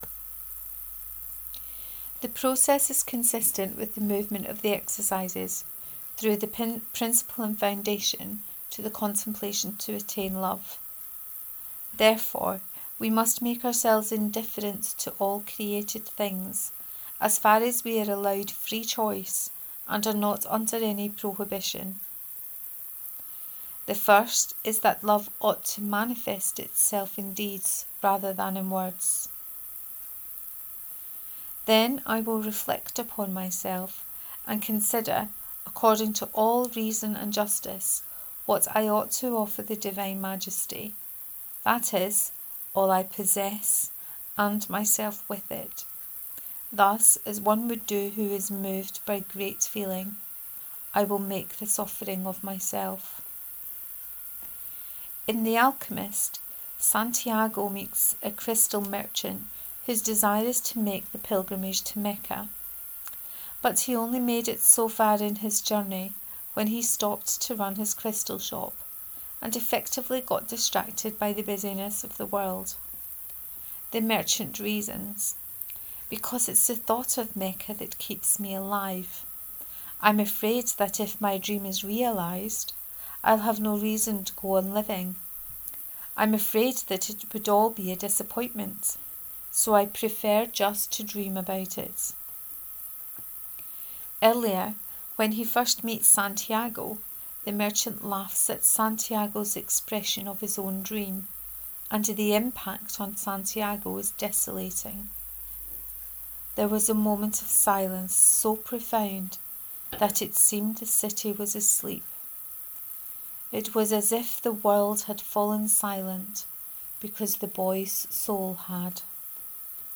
What do you find attractive about Jesus? 3: Reading of this post.